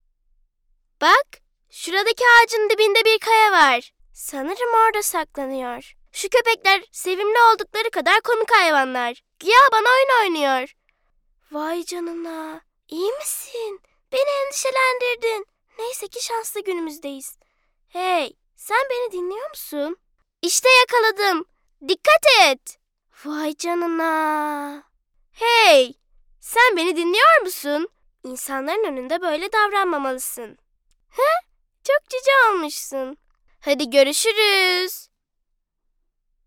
Listen & Download My Voice Samples